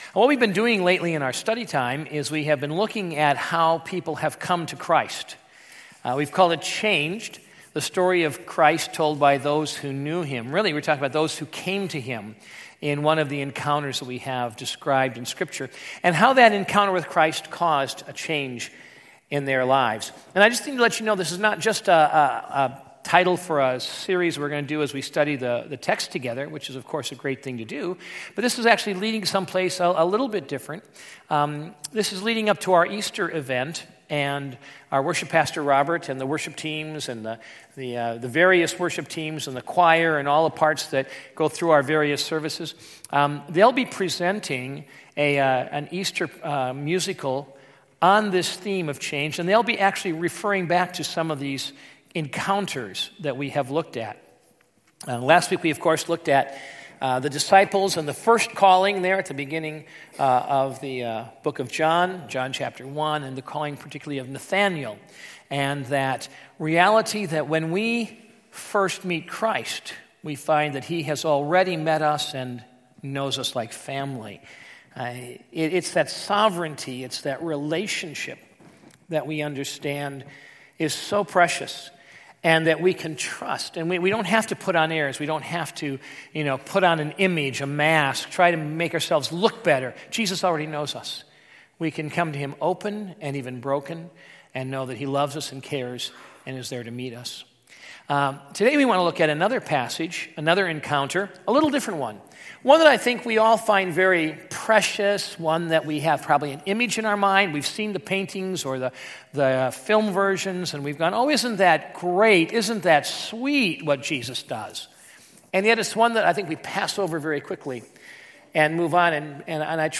2017 Categories Sunday Morning Message Download Audio Matthew 19:13-15